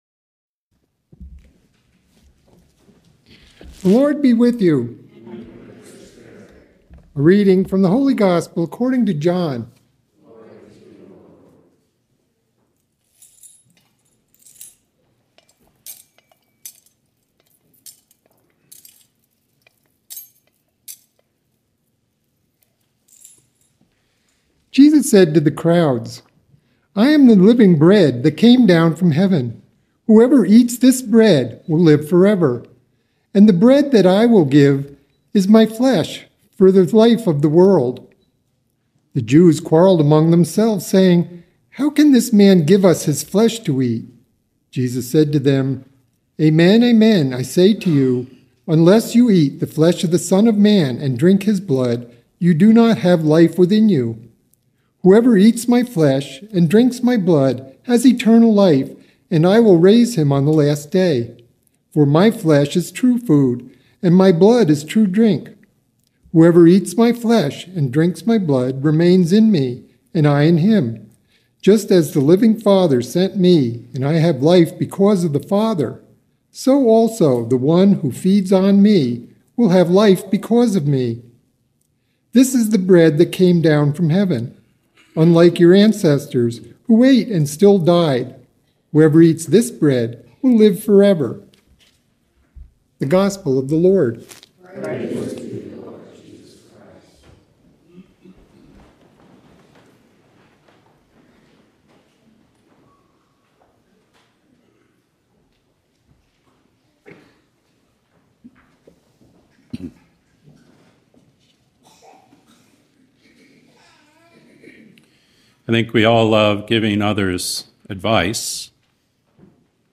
GOSPELS & HOMILIES AUDIO